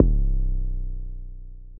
pbs - official oops [ 808 ].wav